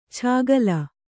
♪ chāgala